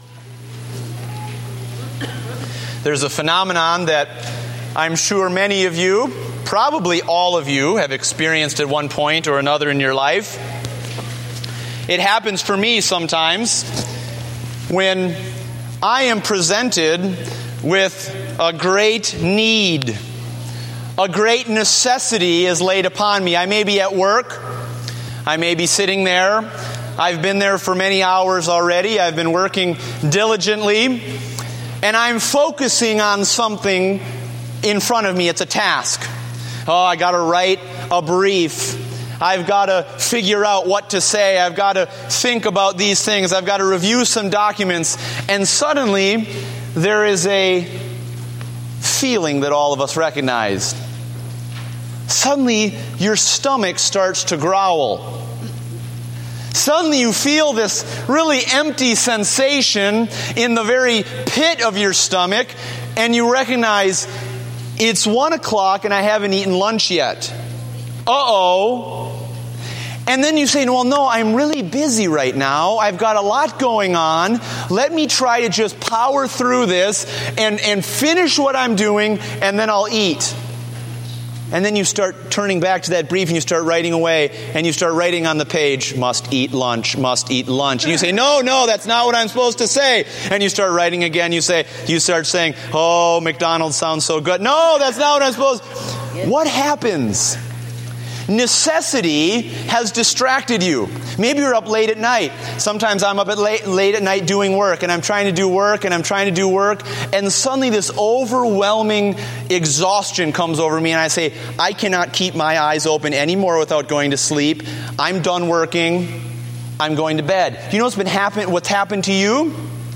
Date: April 12, 2015 (Morning Service)